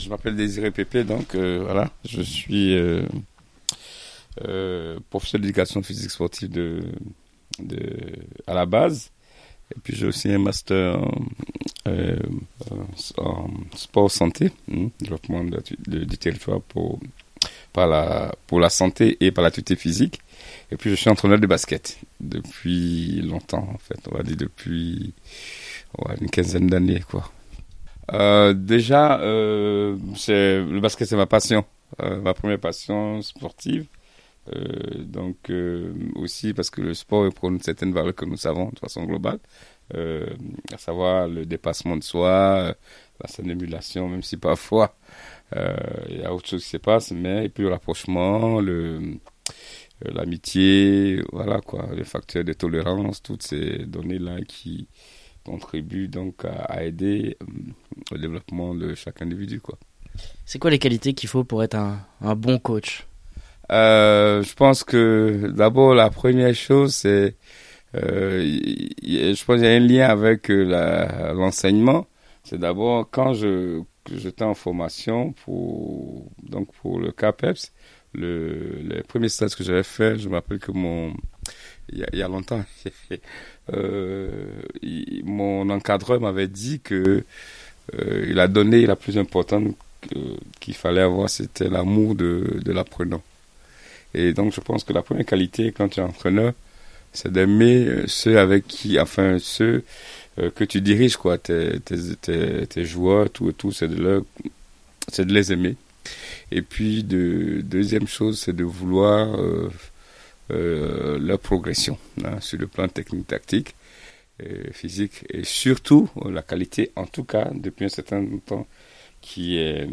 Dans le cadre du Projet Cité Cap, Radio Campus Lorraine réalise une trentaine de portraits radio d’habitants du quartier Vand’Est. La restitution de ces interviews se fera le 23 juin 2018 lors de l’événement Vis dans ta ville à Vandoeuvre-lès-Nancy.